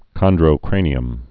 (kŏndrō-krānē-əm)